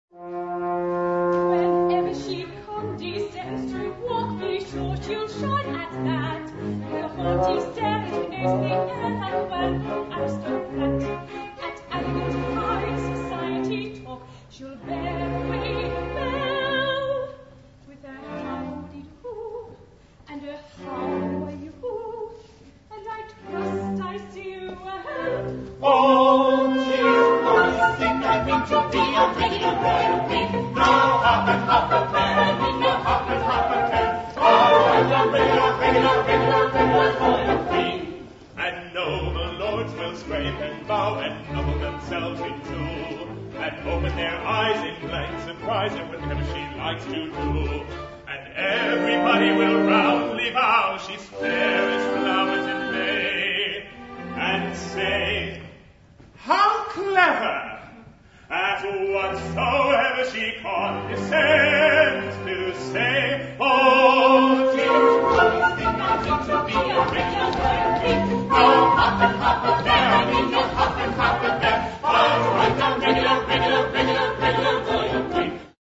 making live recordings of the society's productions.